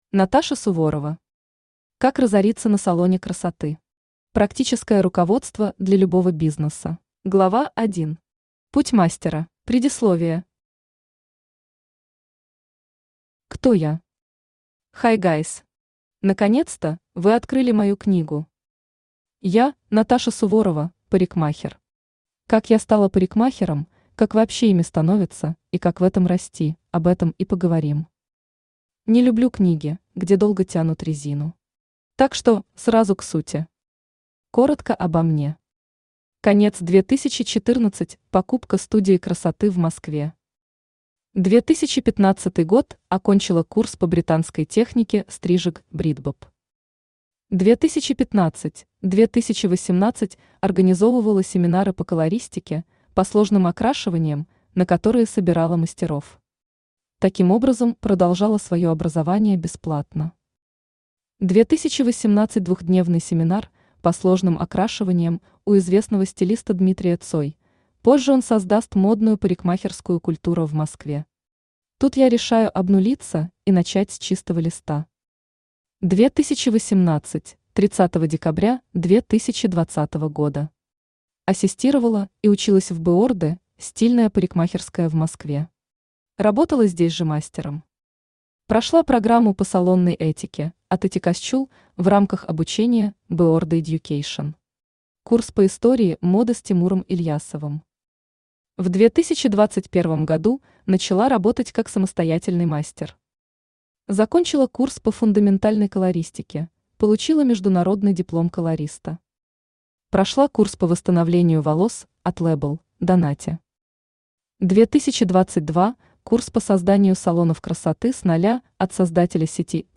Аудиокнига Как разориться на салоне красоты. Практическое руководство для любого бизнеса | Библиотека аудиокниг
Практическое руководство для любого бизнеса Автор Наташа Суворова Читает аудиокнигу Авточтец ЛитРес.